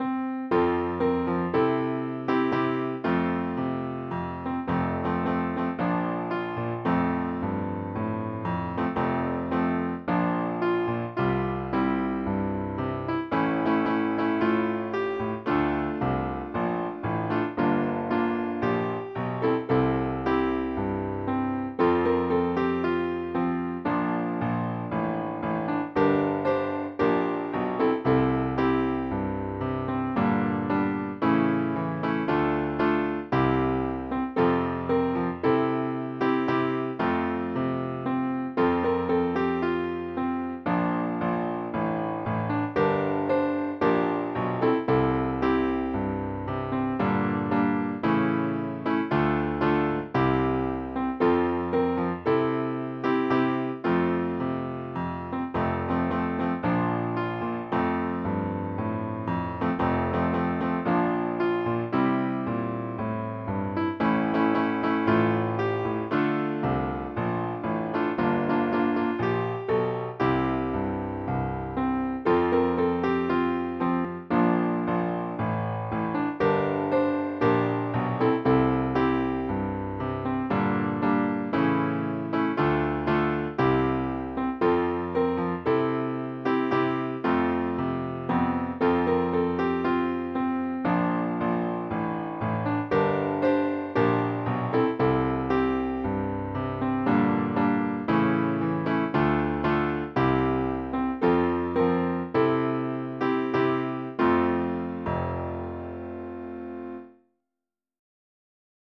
Hymn liceum i zespołu (podkład)